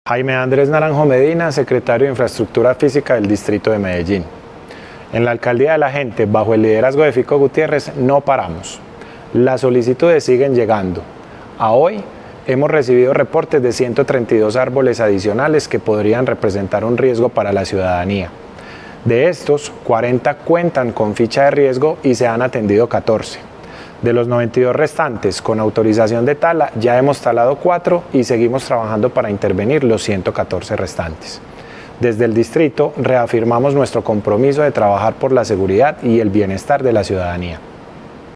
Palabras de Jaime Andrés Naranjo Medina, secretario de Infraestructura Física